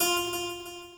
harp1.ogg